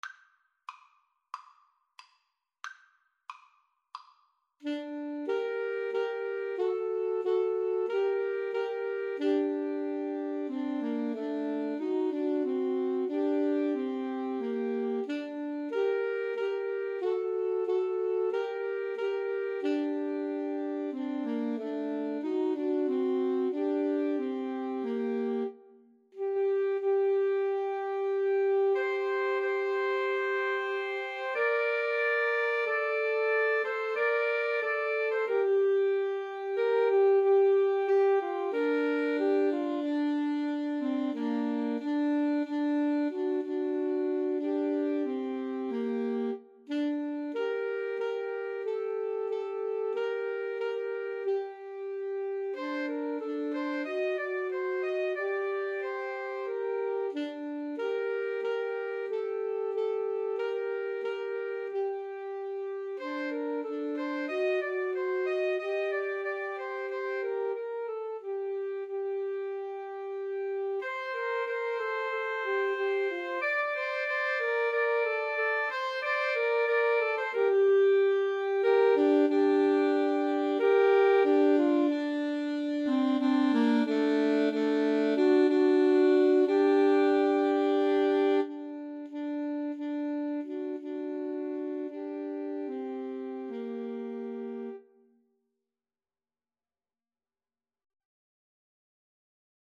Alto Sax Trio version
Andante cantabile ( = c. 92)